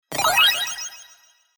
8ビットのリズムが絡む中、爆風音が響き渡り、まるでレトロゲームの中で大きなイベントが起きたかのような迫力ある通知音。